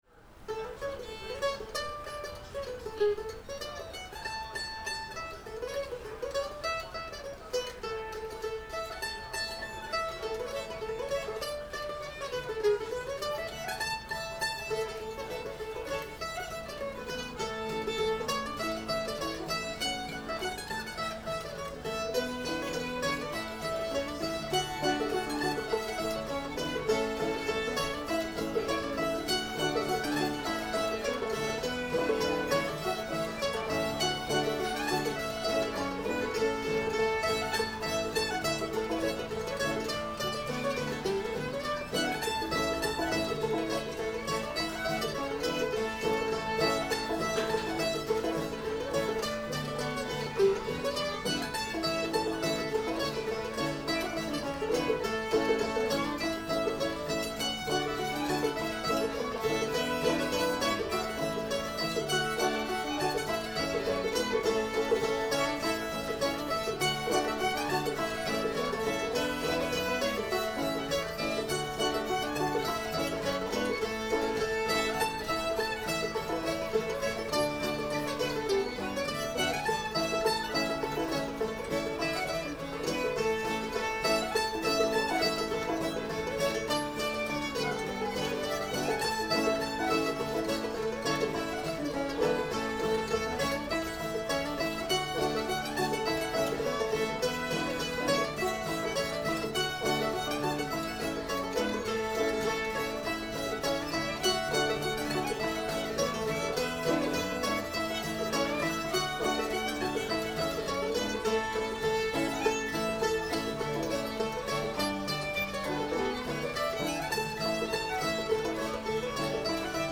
A modal